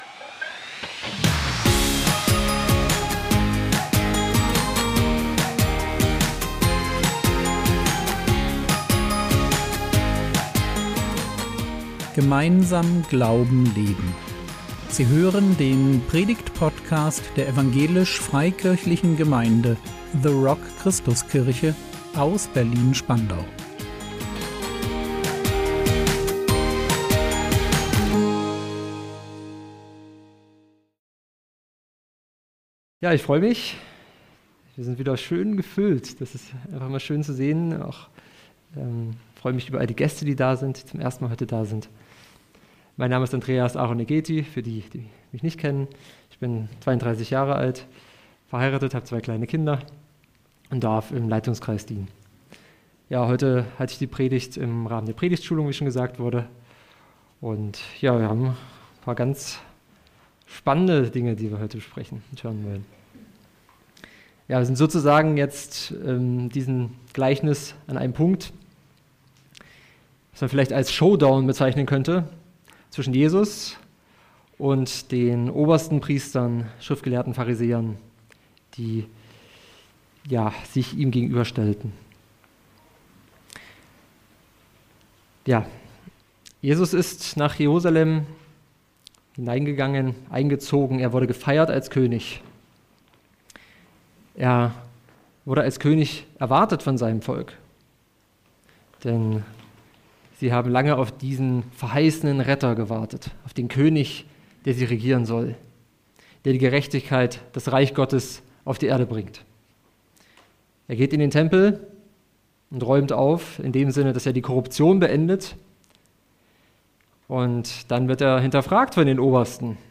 Ein Fest für alle: Wer nimmt die Einladung an? | 17.11.2024 ~ Predigt Podcast der EFG The Rock Christuskirche Berlin Podcast